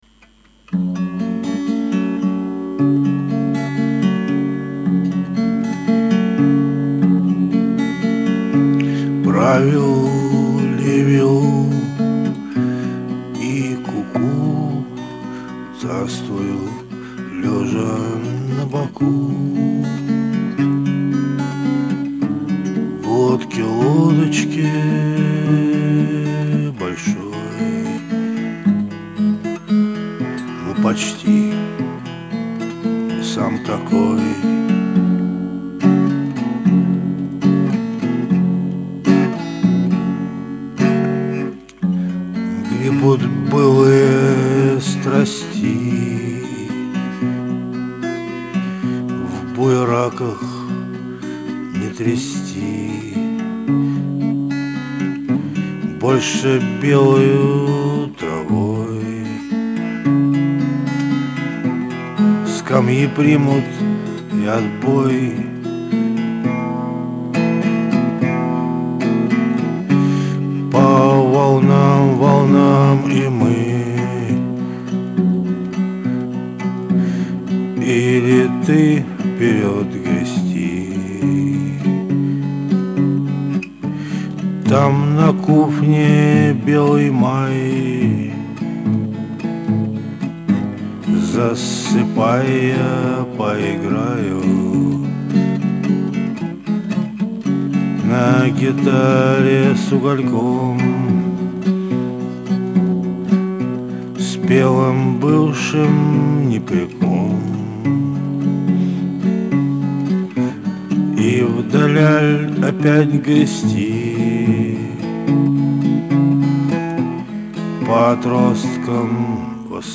"в куфне" перепел